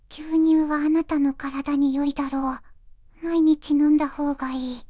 voicevox-voice-corpus
voicevox-voice-corpus / ita-corpus /中国うさぎ_こわがり /EMOTION100_029.wav